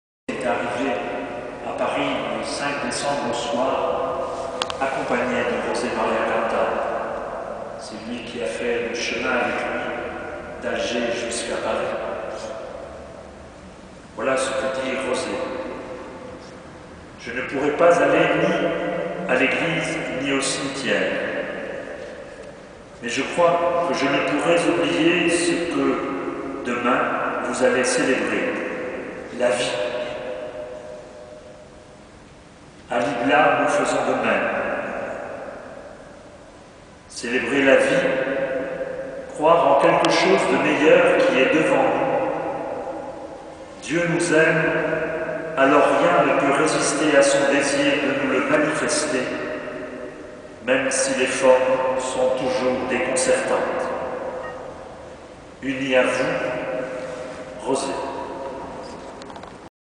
OBSEQUES
en l’église Saint Pierre de Montrouge à Paris